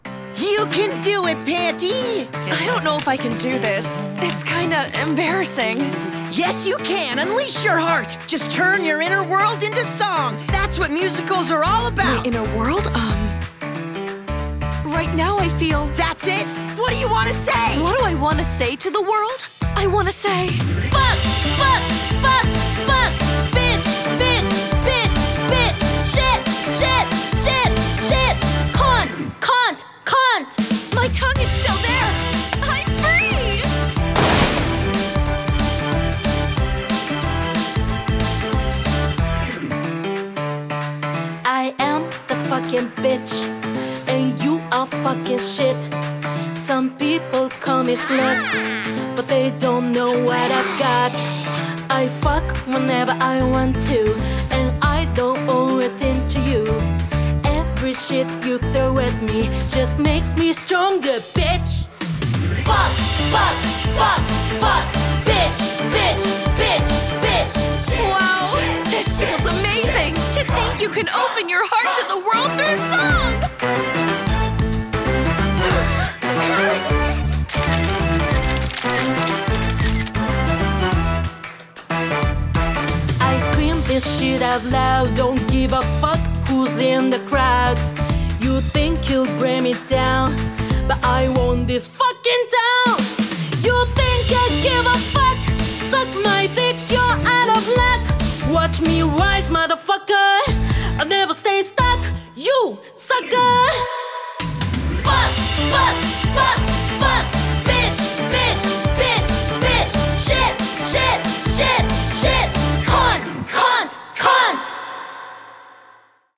BPM184
Audio QualityCut From Video